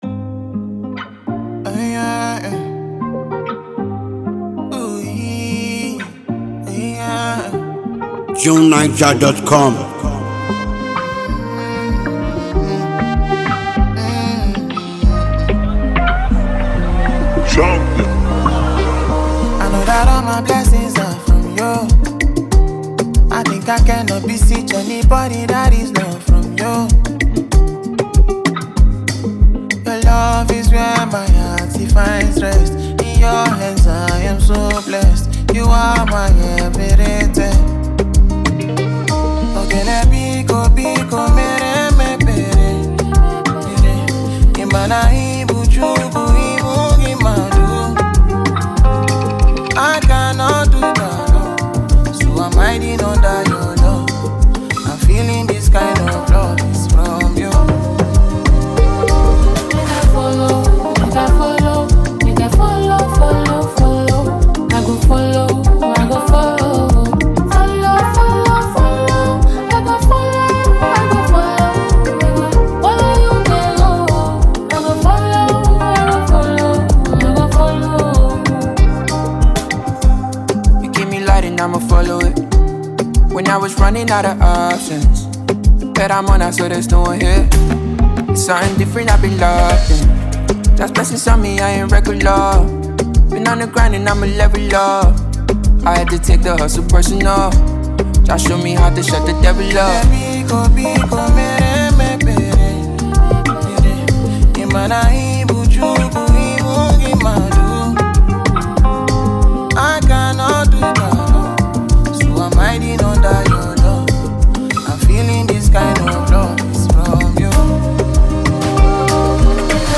captivating and mind-blowing new electric jam
a fantastic and incredibly talented vocalist and music star.